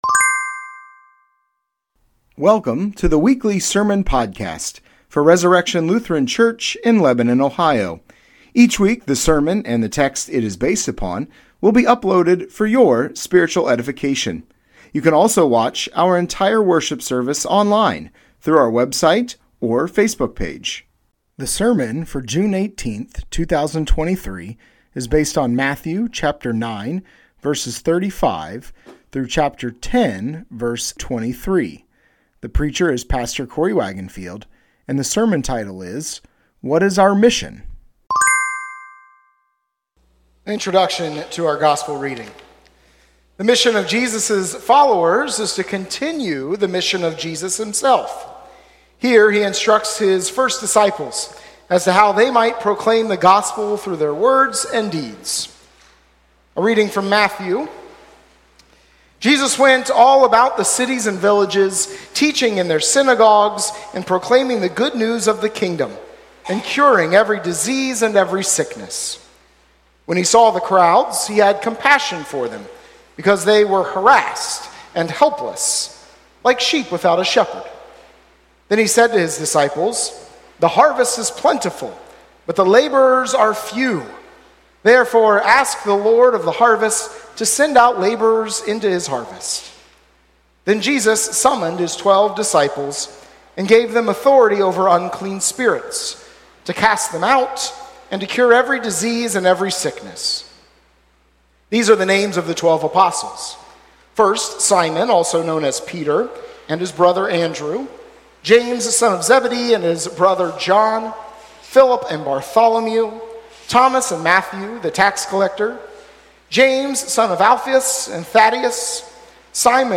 Sermon Podcast Resurrection Lutheran Church - Lebanon, Ohio June 18, 2023 - "What Is Our Mission?"